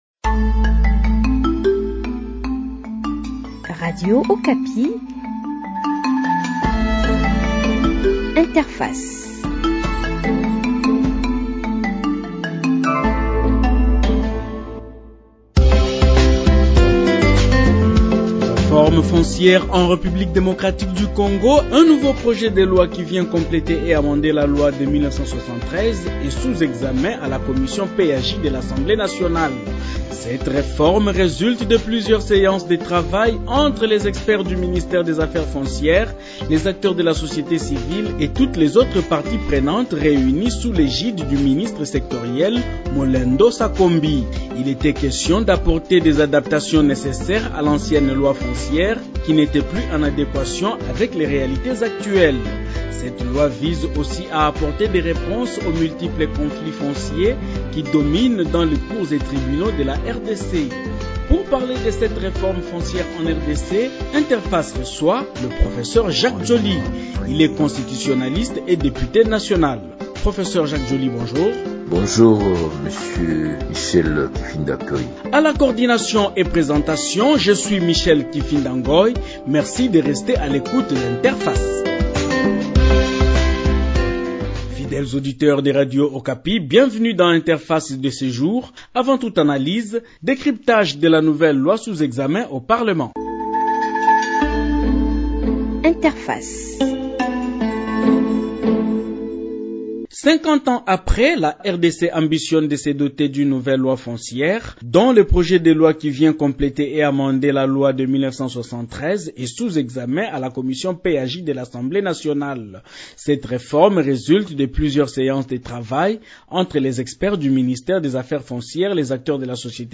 Pour parler de cette reforme foncière en RDC, Interface reçoit le professeur Jacques Djoli. Il est constitutionnaliste et député national.